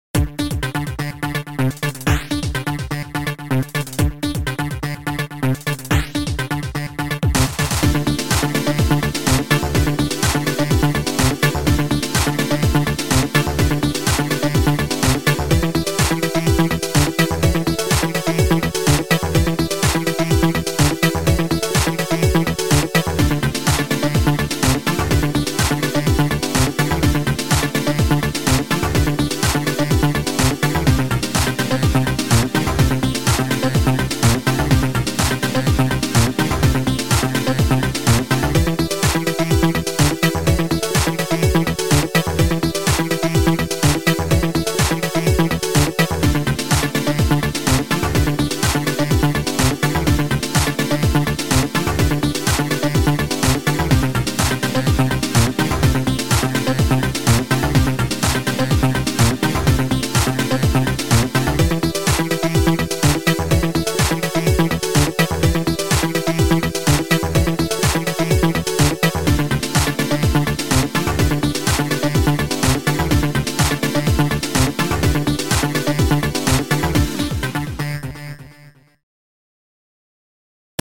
Sound Format: Noisetracker/Protracker
Sound Style: Rock